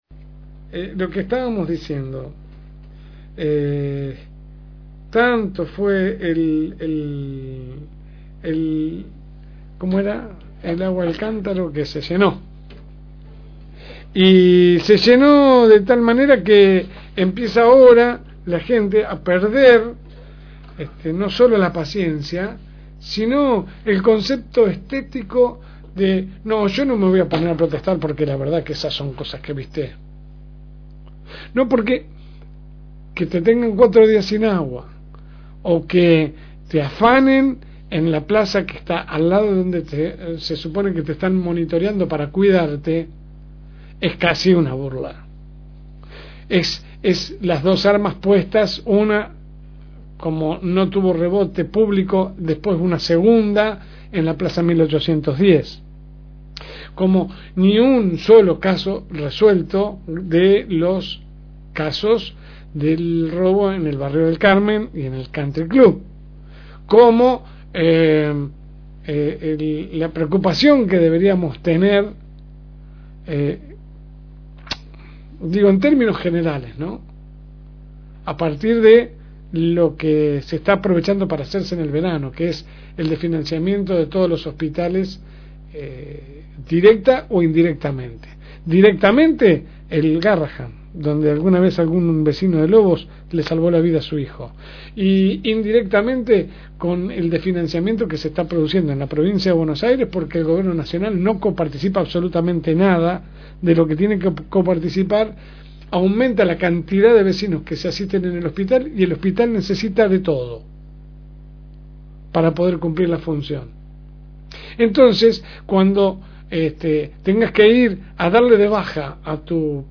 AUDIO – Editorial de la LSM. – FM Reencuentro